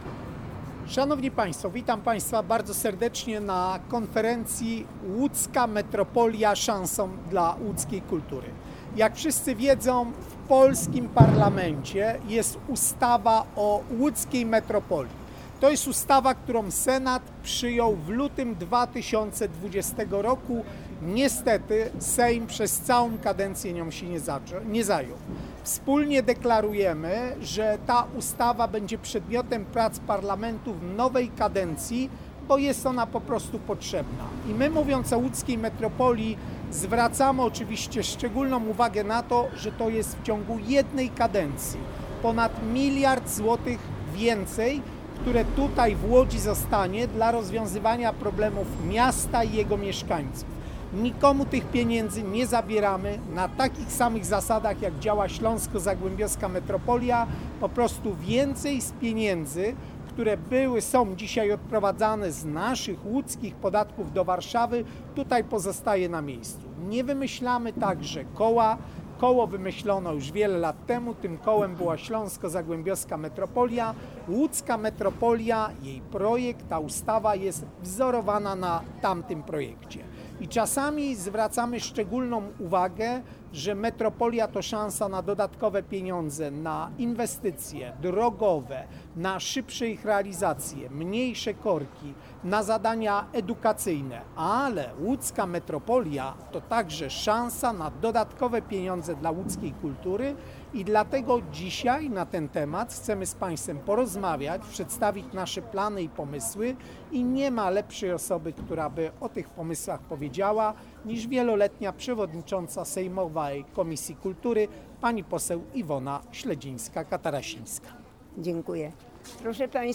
kwiatkowski-konferencja-2709-calosc.mp3